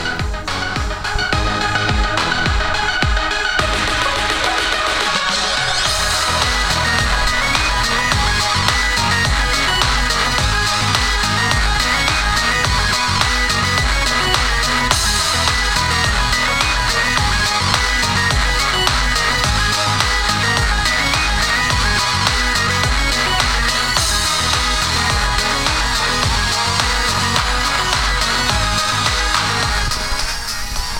・ステレオICレコーダー：オリンパス LS-20M リニアPCM ハイレゾ96kHz/24bit 非圧縮WAV形式
ブログのサーバーへのアップロード容量が少ないので、ハイレゾで録音できる時間は３０秒程度になりました。
リンクスのハイレゾイヤフォンは、逆に高音域が強調されすぎて、キンキンした感じがします。